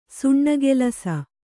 ♪ suṇṇagelasa